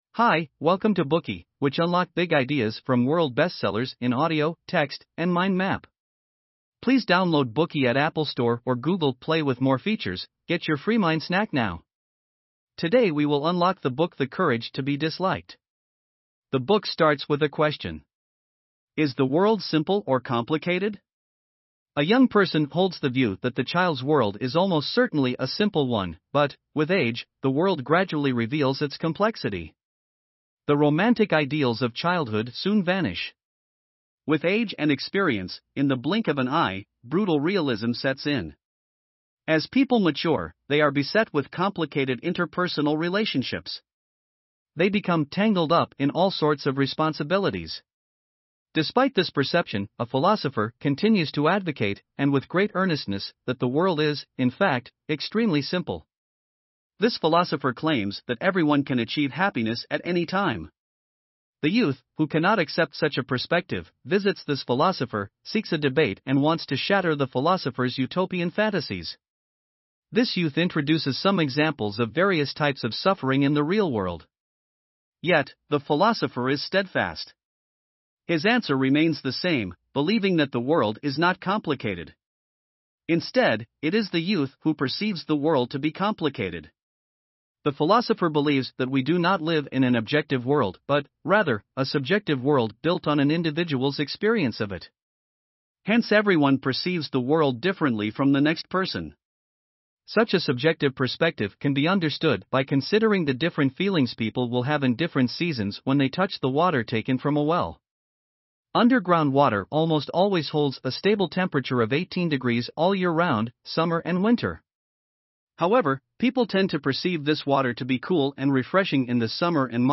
The Courage to be Disliked Full Free Audio Book Summary - BOOKEY Book Summary and Review